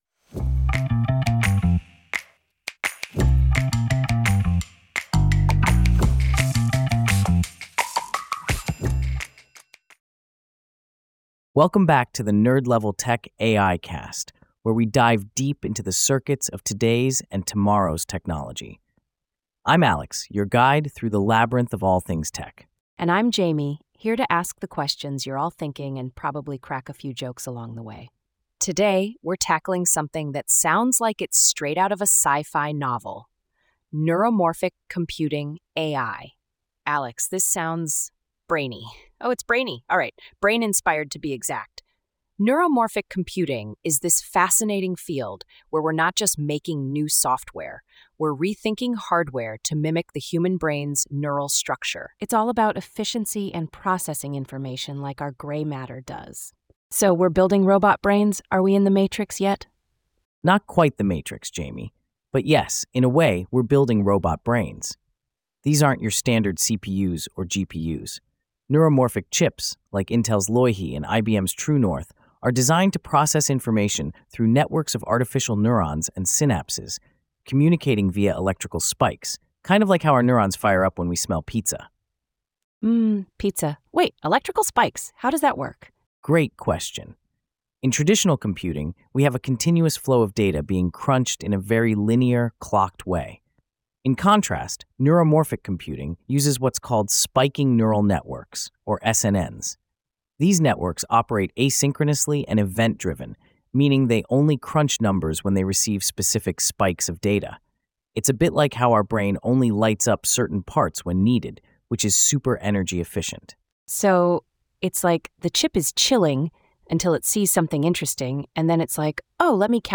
AI-generated discussion